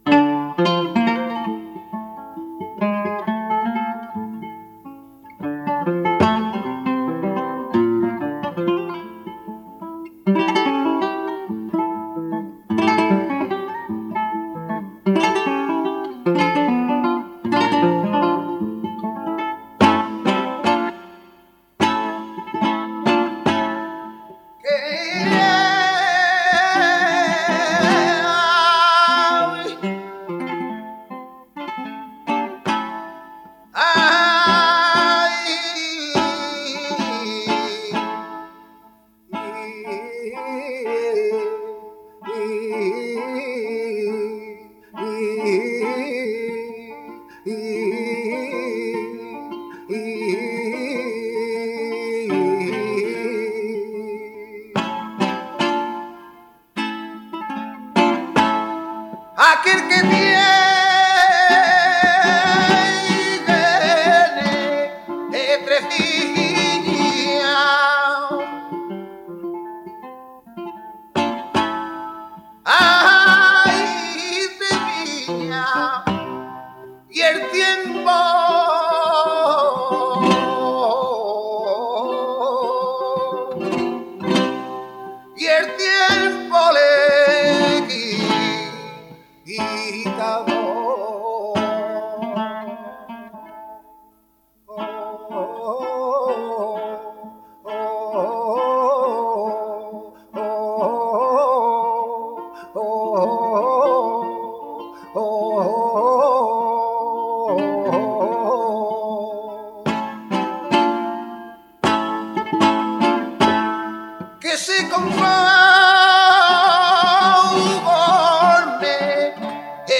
Caña / 1